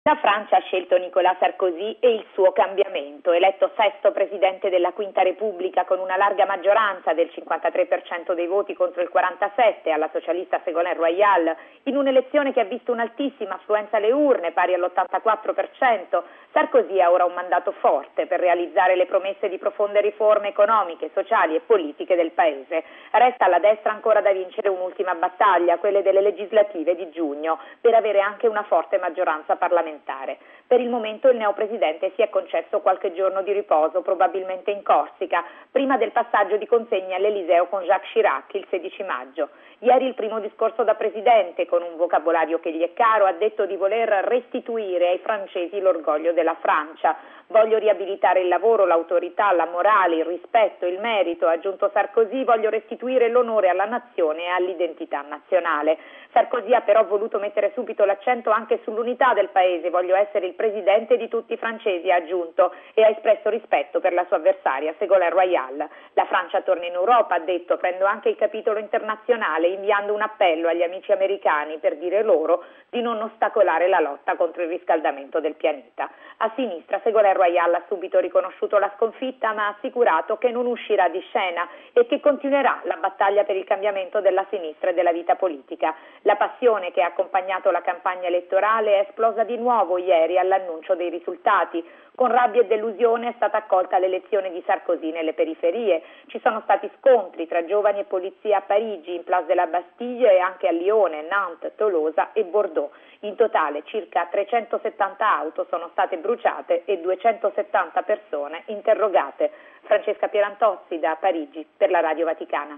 Radio Vaticana - Radiogiornale